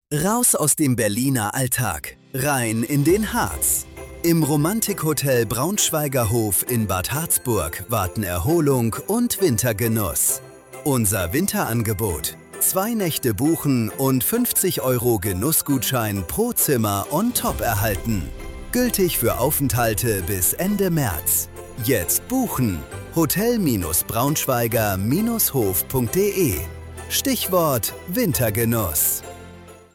Braunschweiger-Hof-Spot-Wintergenuss_Genussgutschein_29s.mp3